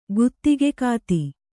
♪ guttigekāti